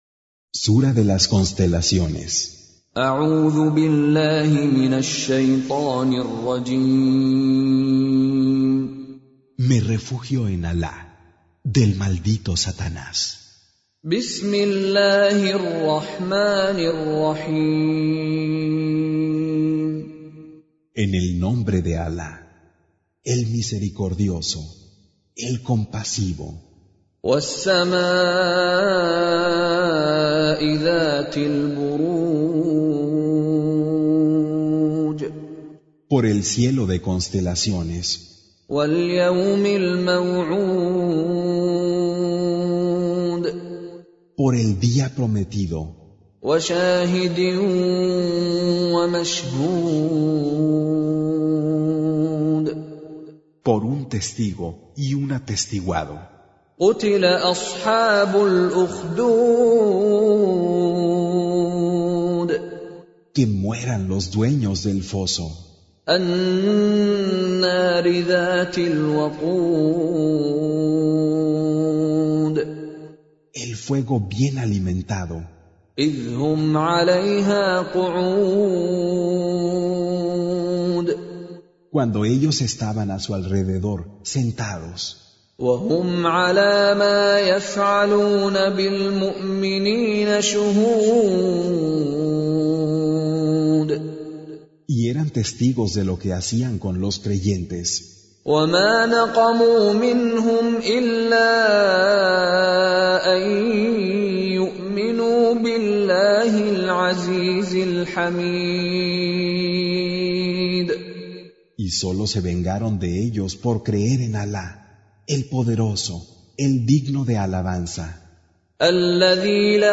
Recitation
Con Reciter Mishary Alafasi